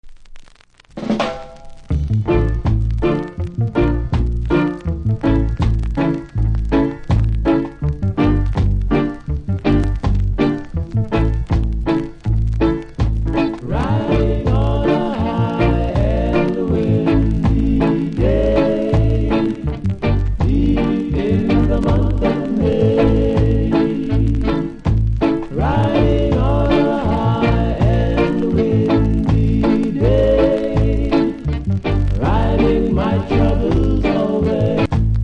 両面全体的にキズあり盤の見た目悪いですが、多少のノイズは気にならない方ならプレイ可レベル。